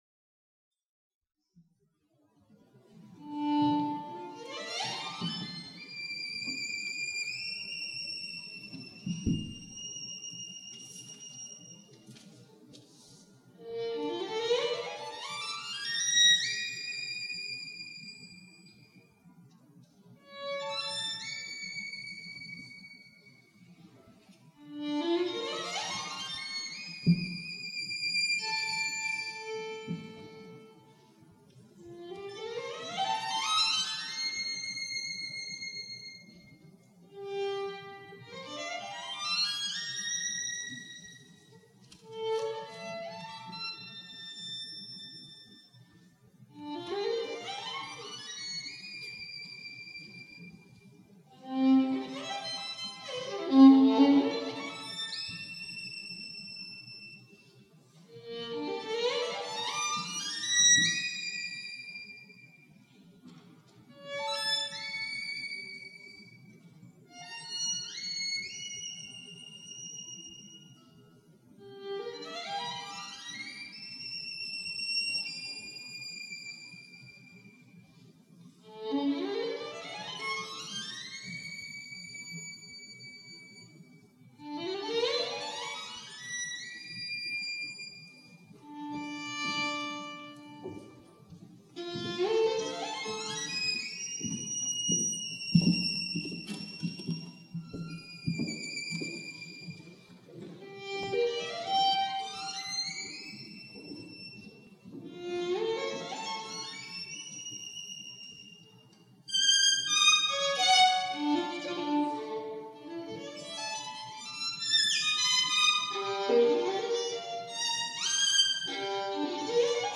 British Museum